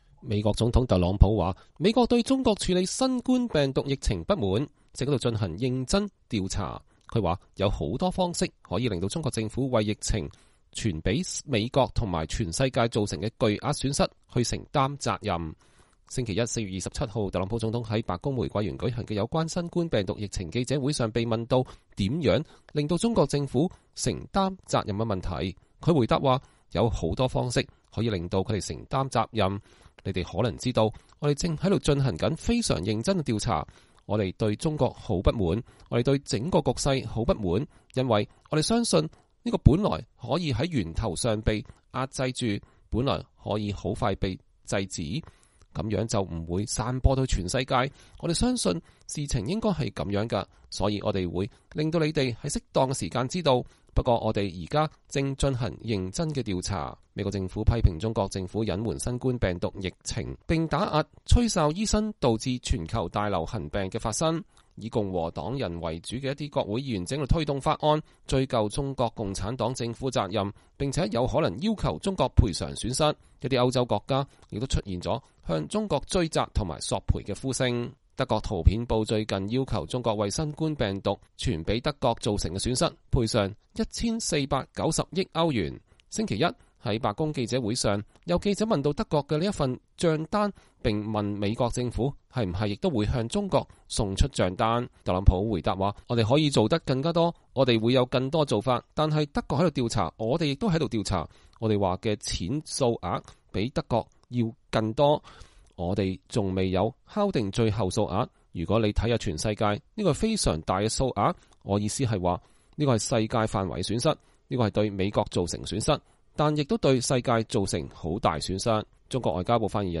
特朗普總統在白宮玫瑰園就新冠病毒疫情舉行記者會。(2020年4月27日)